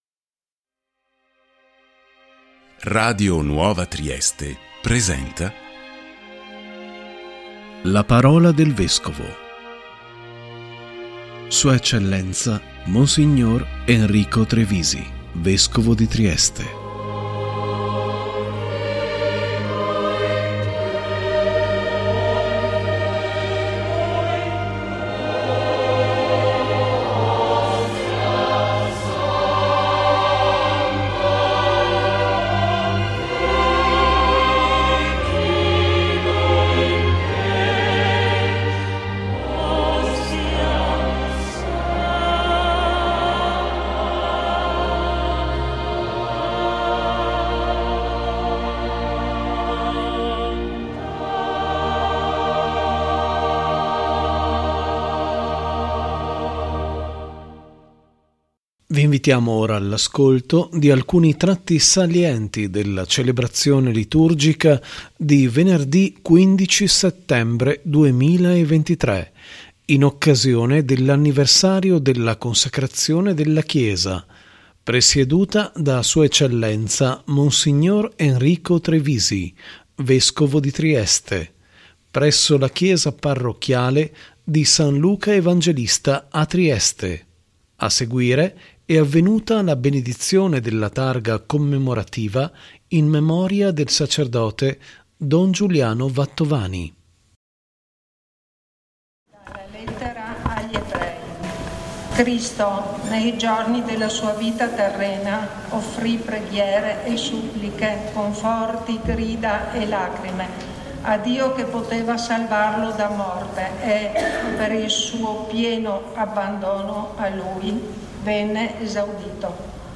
♦ si è tenuta, la celebrazione liturgica di Venerdì 15 settembre 2023, in occasione dell’Anniversario della Consacrazione della chiesa, presieduta da Sua Eccellenza Mons. Enrico Trevisi, Vescovo di Trieste, presso la Chiesa Parrocchiale di San Luca Evangelista a Trieste.